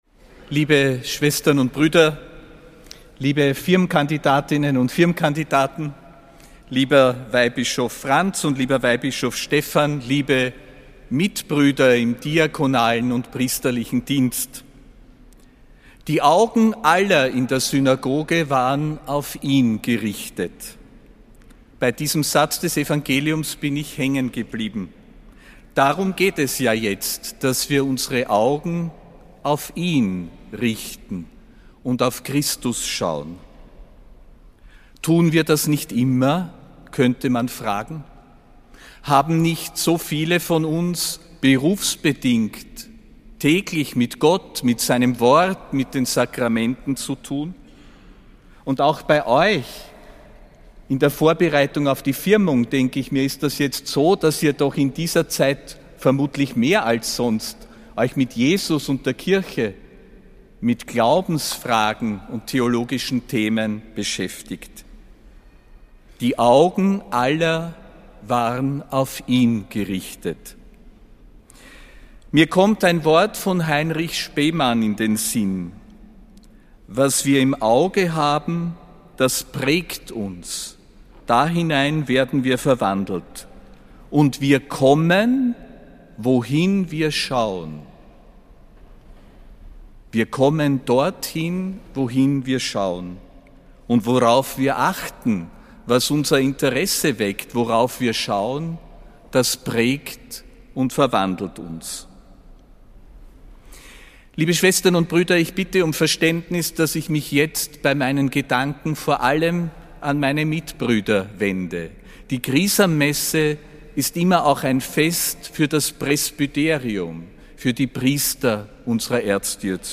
Predigt des Apostolischen Administrators Josef Grünwidl zur Chrisammesse, am 14. April 2025.